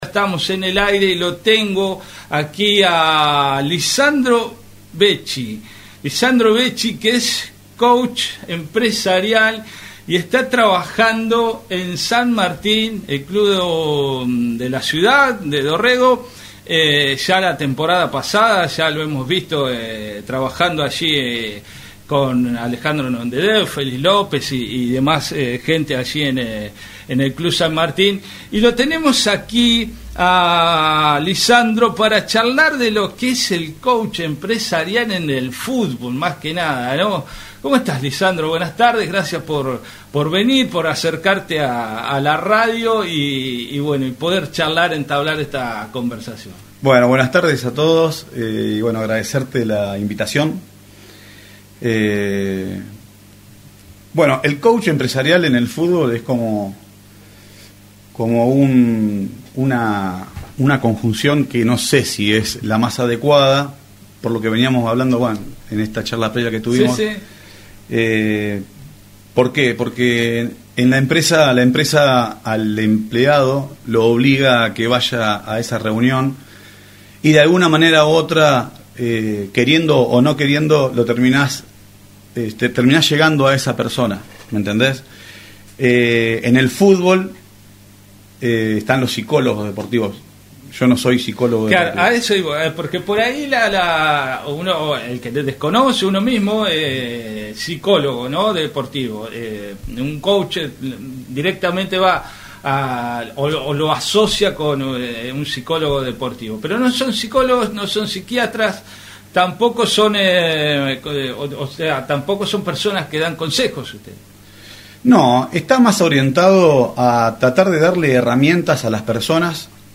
Entrevista en LA DORREGO